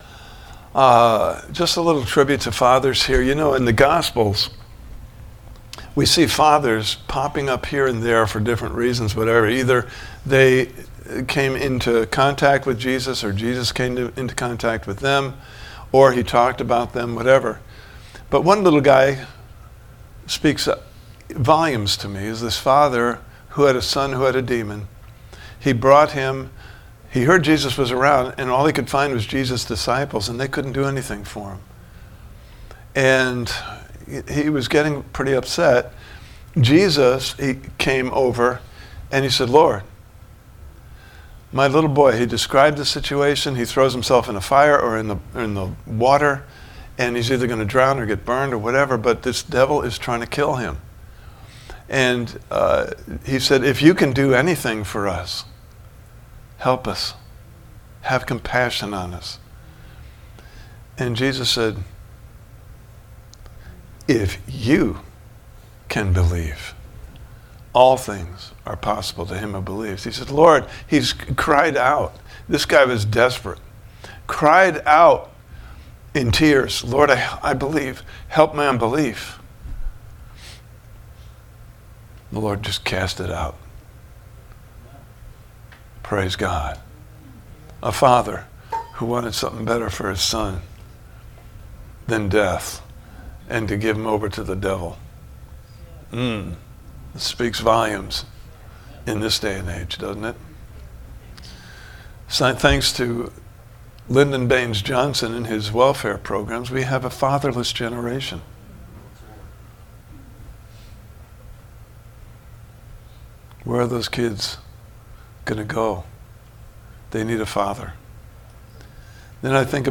Series: Why Do Bad Things Happen To Good People? Service Type: Sunday Morning Service « Part 2: What Does God Allow?